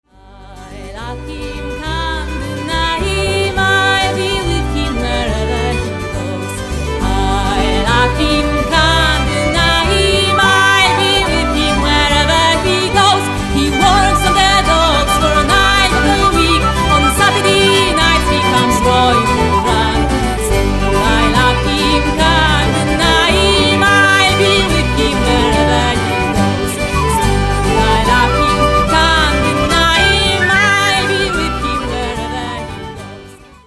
Zespół Forann prezentuje repertuar inspirowany etniczną muzyką ludów celtyckich, a w szczególności muzyką Walii, Szkocji, Bretanii i Irlandii.
skrzypce
gitara basowa
akordeon
flet poprzeczny